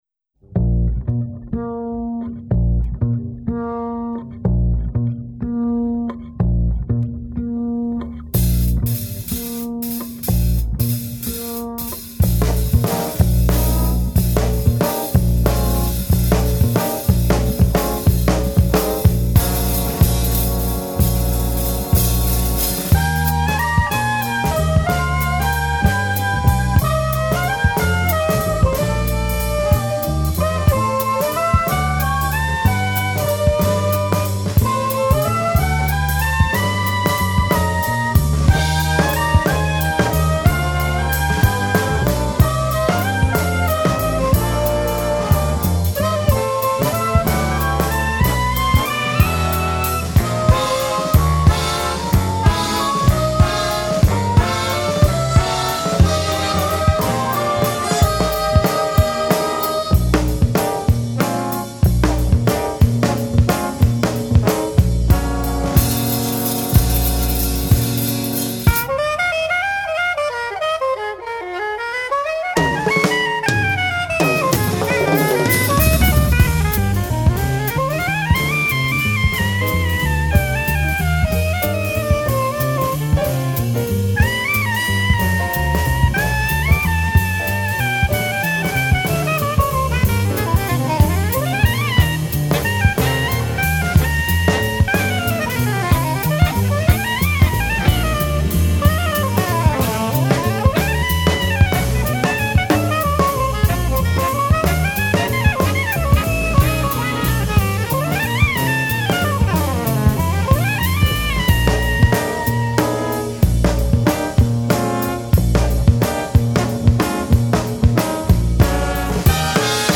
Big Band
Studioproduktion